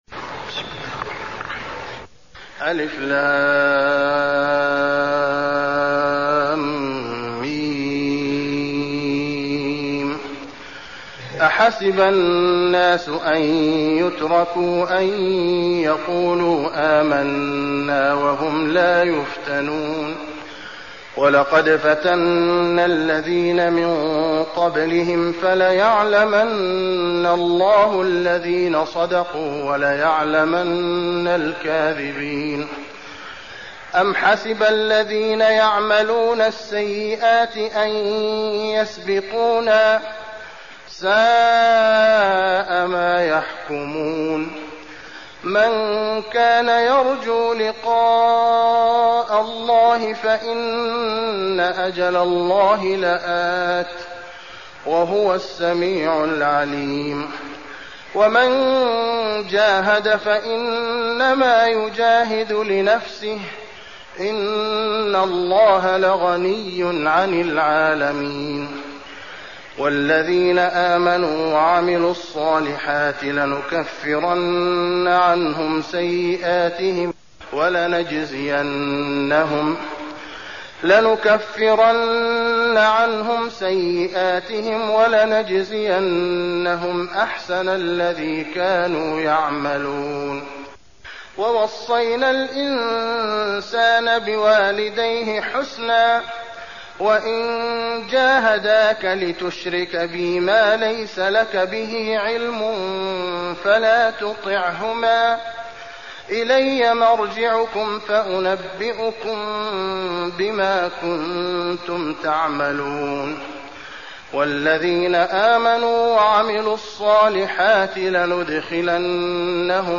المكان: المسجد النبوي العنكبوت